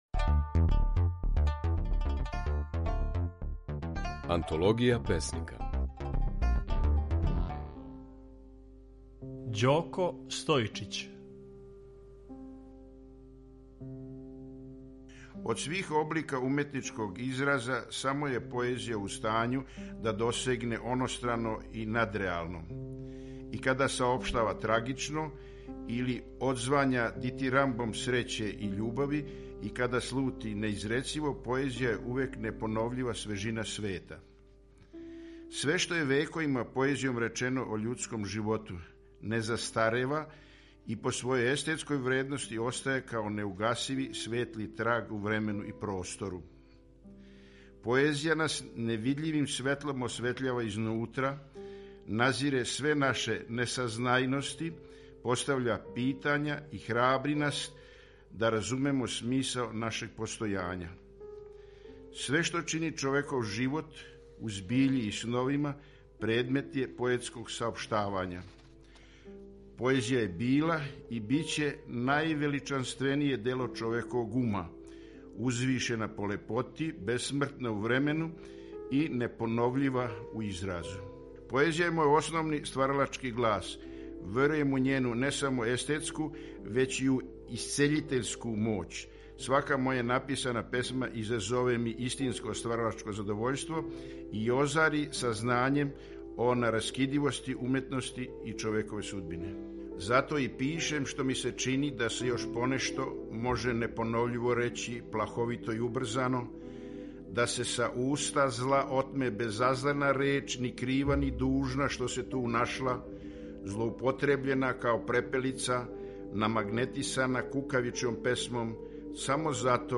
У oвој емисији можете чути како своје стихове говори Ђоко Стојичић.
Емитујемо снимке на којима своје стихове говоре наши познати песници